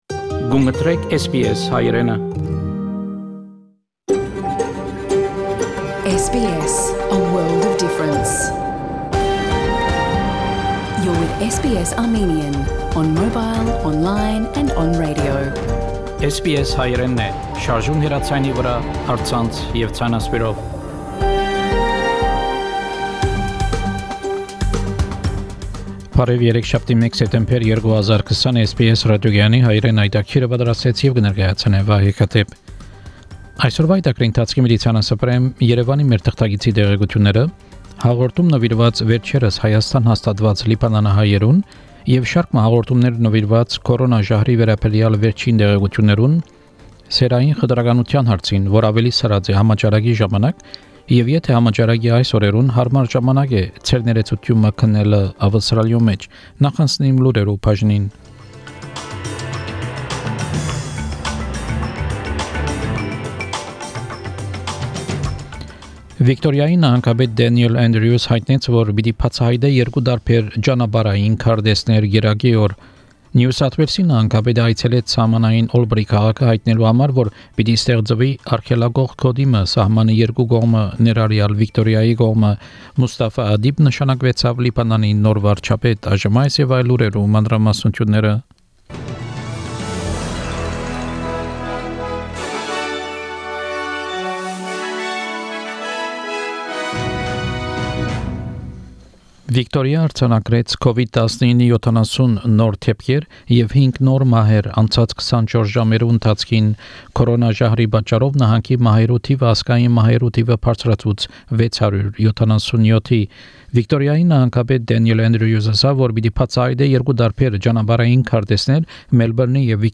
SBS Armenian news bulletin – 1 September 2020
SBS Armenian news bulletin from September 1, 2020 program.